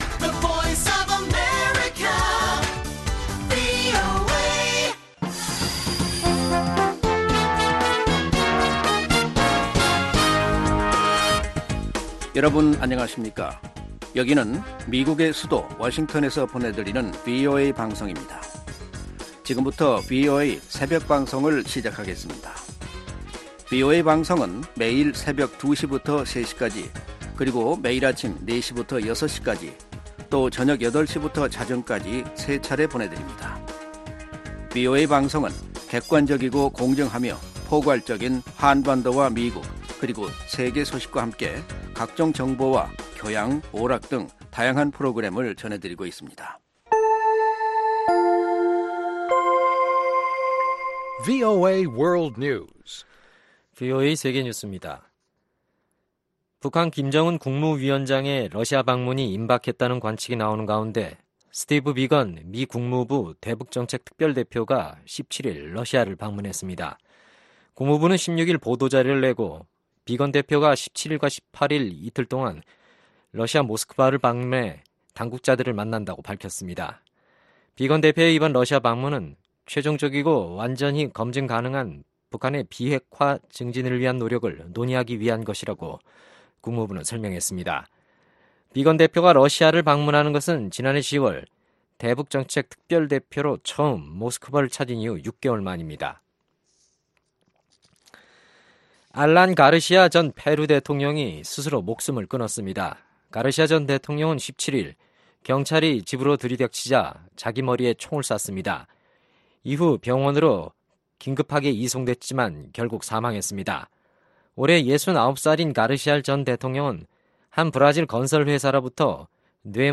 VOA 한국어 '출발 뉴스 쇼', 2019년 4월 18일 방송입니다. 스티븐 비건 미국 국무부 대북정책 특별대표가 북한 비핵화 문제를 논의하기 위해 이번 주 러시아를 방문합니다. 두 차례의 미-북 정상회담 이후 도널드 트럼프 대통령과 김정은 북한 국무위원장 간 ‘톱 다운’식 비핵화 협상에 대한 미 의원들의 회의적 목소리는 더 높아졌습니다.